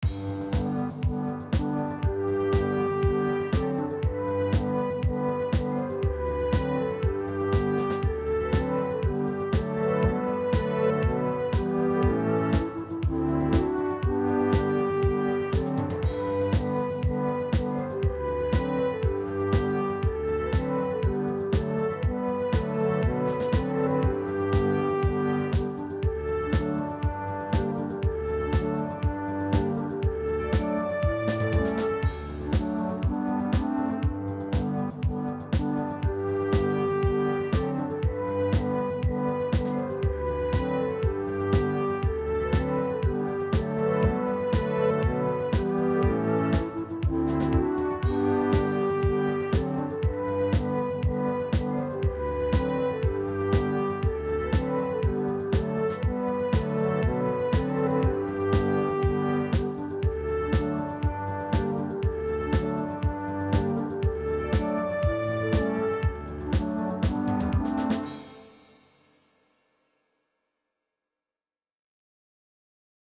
Für die Klangbeispiele habe ich das Augmented Piano und die Augmented Horns und Brass verwendet.
augumented-brass-und-woodwind.mp3